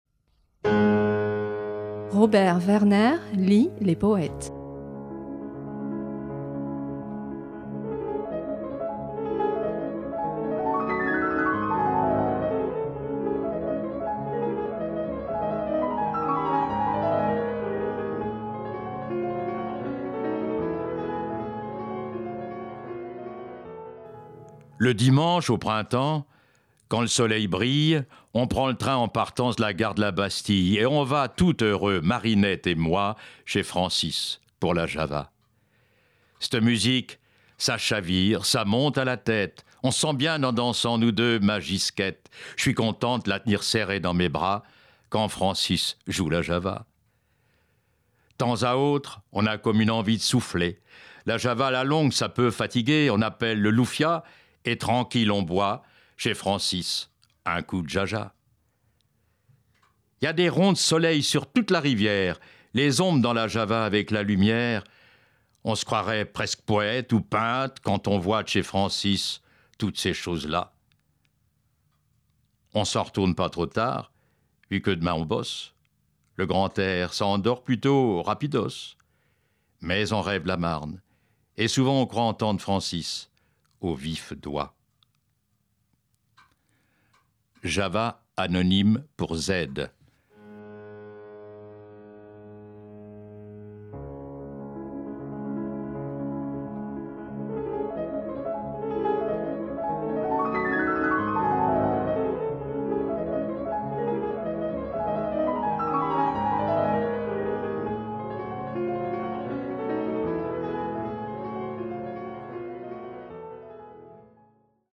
lit cette semaine Java, un poème anonyme, à la fois joyeux et mélancolique, évocateur des guinguettes d’antan et des sentiments de tout temps.